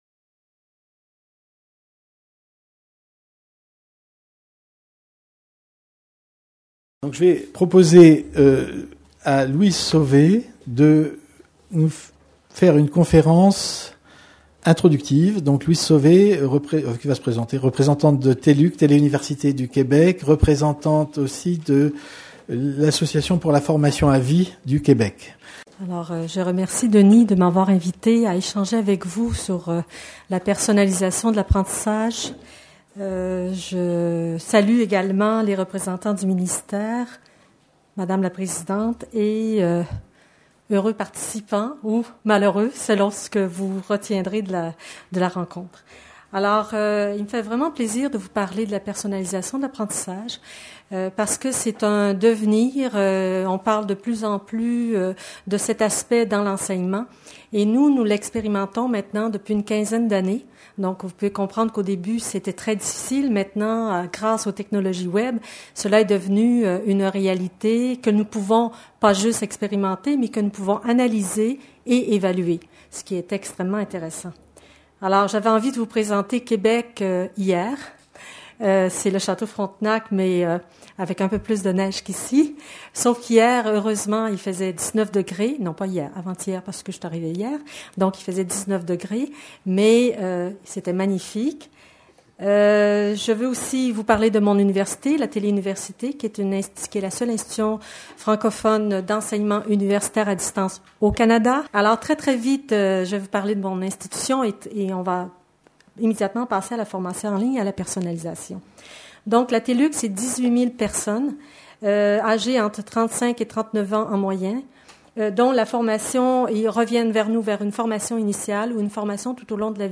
Journées nationales AUNEGE 2012
Conférence inaugurale